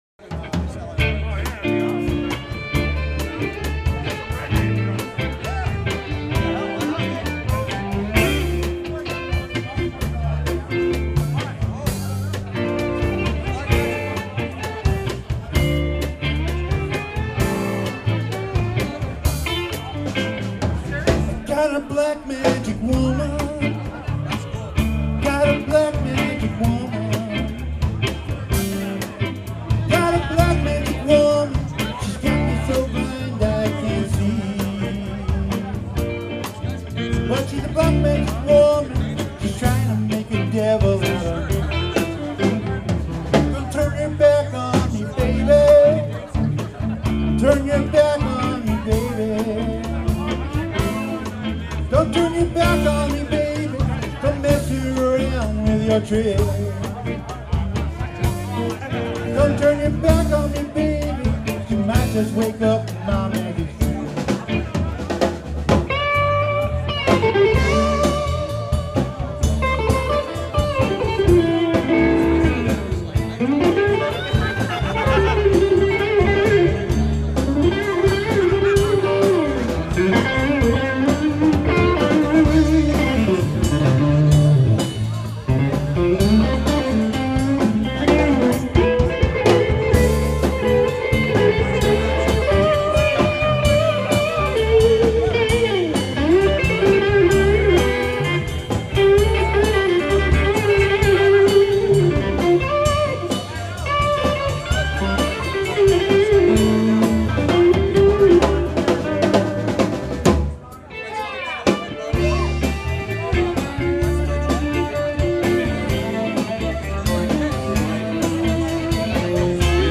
vocals, guitar
bass, vocals
drums
Violin
Recorded live at Sally O'Brien's, Somerville, MA on 5/4/08.